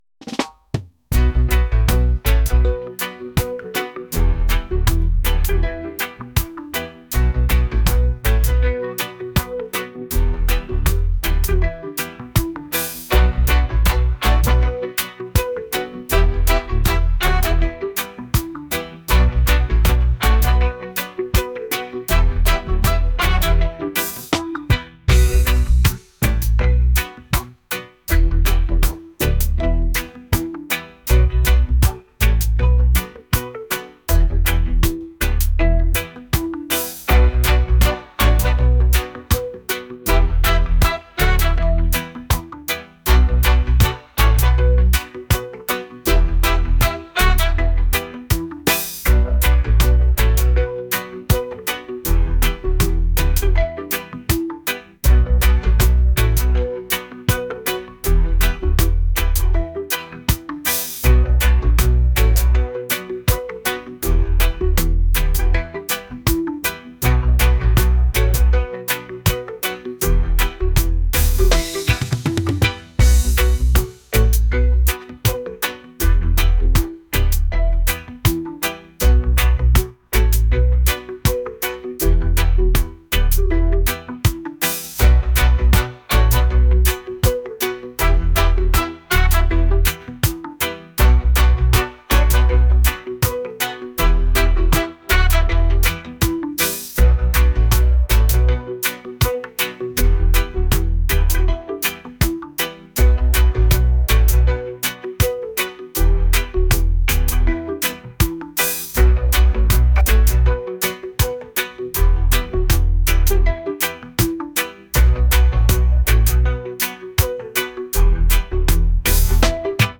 reggae | lounge | acoustic